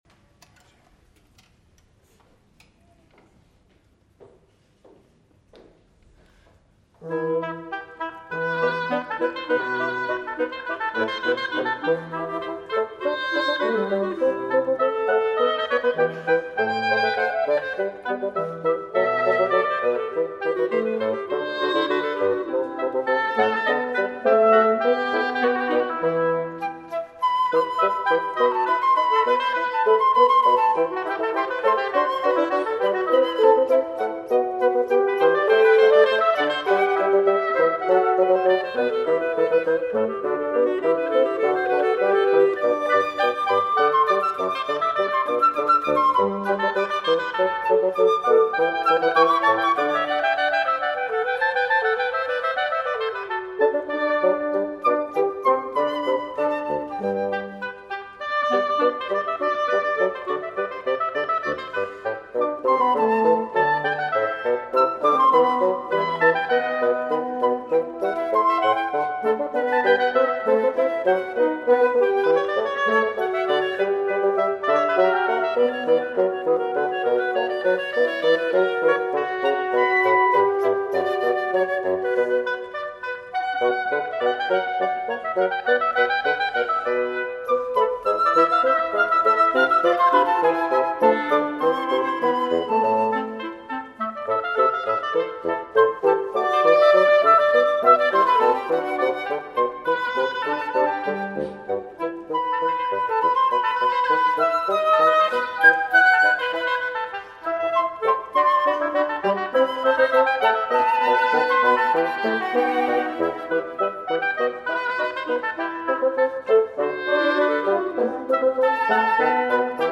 George Fr. Haendel (Α. Μπαλτάς) – “Blessed is the People” για Κουιντέτο Πνευστών (live)
Κουιντέτο ξύλινων πνευστών
φλάουτο
όμποε
κλαρινέτο
φαγκότο
κόρνο